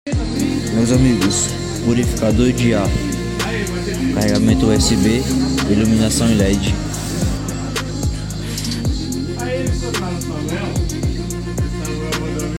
# purificador de ar sound effects free download